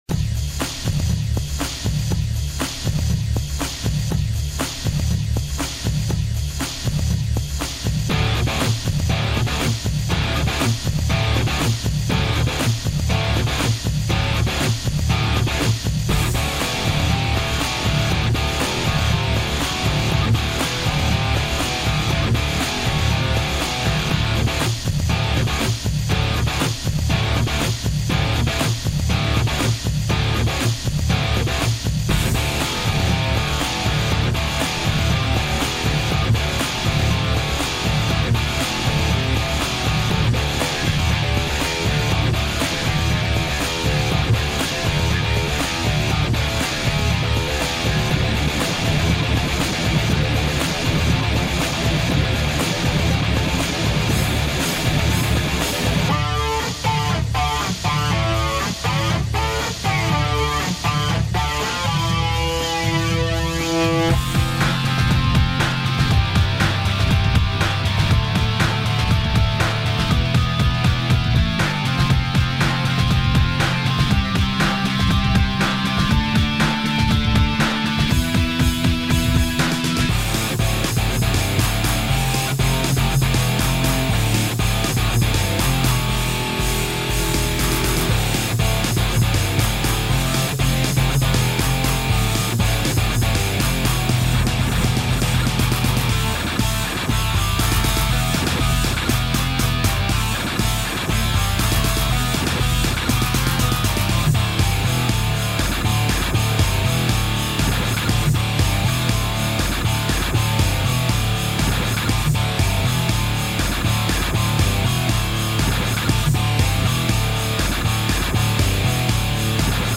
フリーBGM 戦闘曲